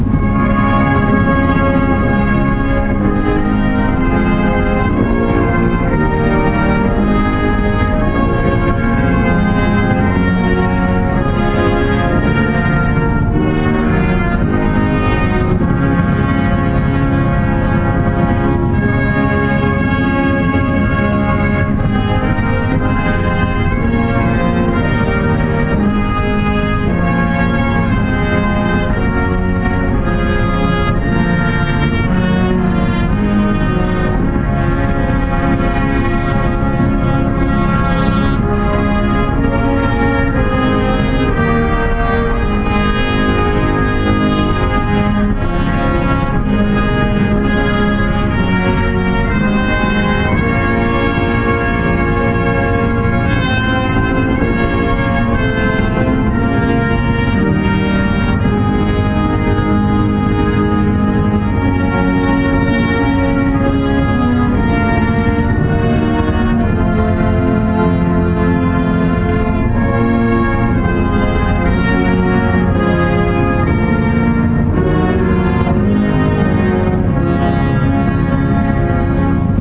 "Ein feste Burg" performed by the United States Army Band in December 2018.flac